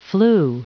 Prononciation du mot flew en anglais (fichier audio)
Prononciation du mot : flew